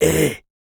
Male_Grunt_Hit_14.wav